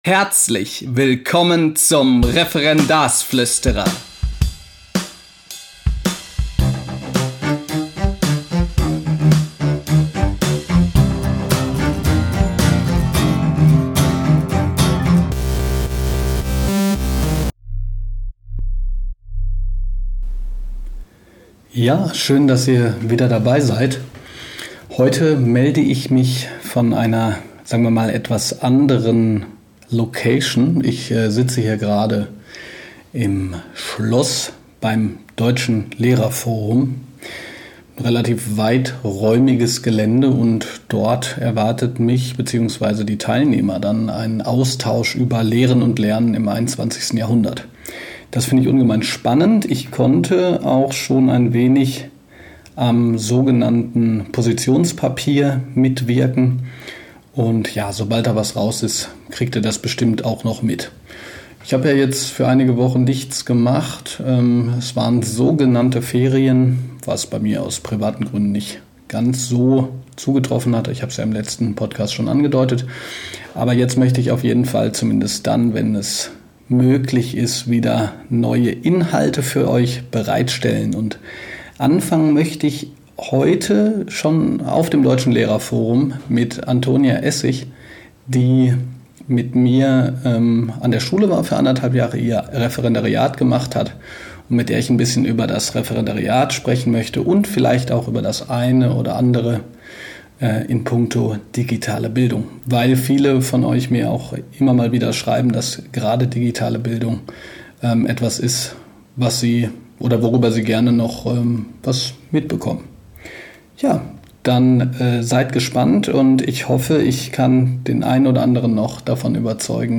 Diese Folge ist das erste einiger Gespräche mit interessanten Persönlichkeiten, die ich auf dem Deutschen Lehrerforum geführt habe.